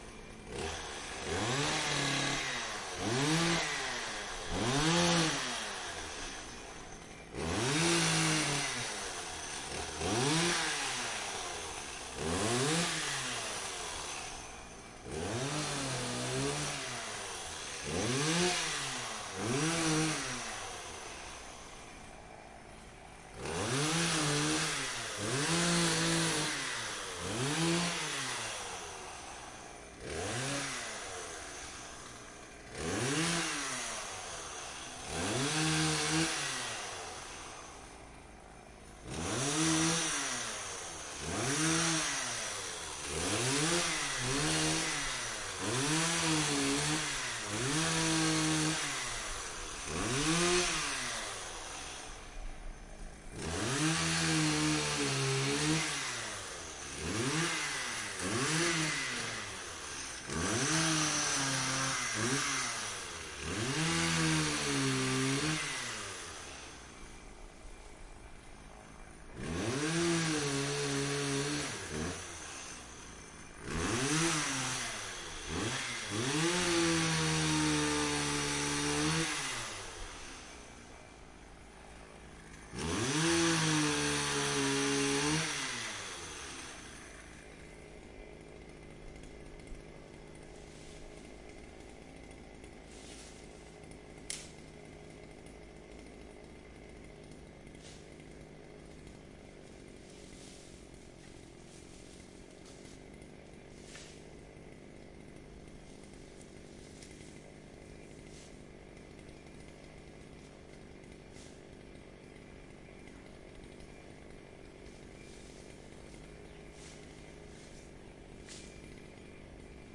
电锯 " 电锯锯木 + 冰箱远2
Tag: 链锯 空转 到目前为止